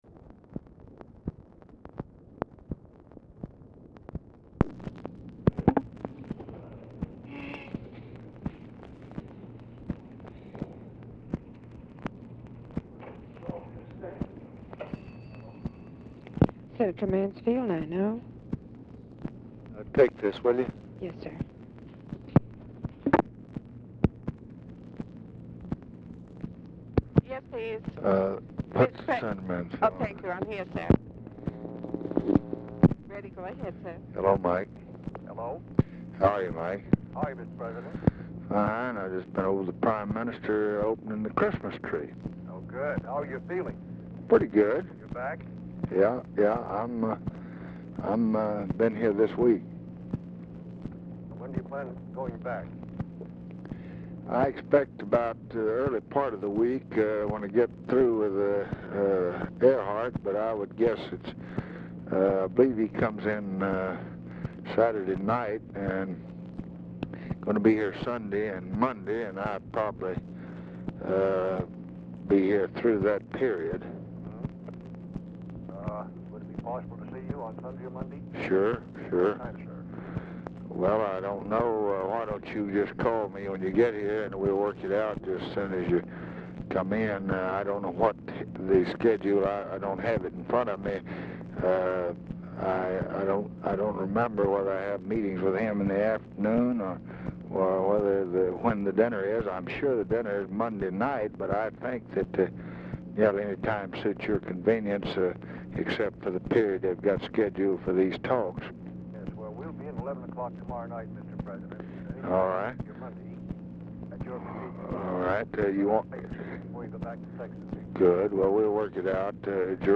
Oval Office or unknown location
"HONOLULU, HAWAII"; "(RET. HIS CALL)"; SPEED OF ORIGINAL DICTABELT RECORDING SLIGHTLY FAST; SPEED ADJUSTED BY LBJ LIBRARY STAFF; MANSFIELD ON HOLD 0:35
Telephone conversation
Dictation belt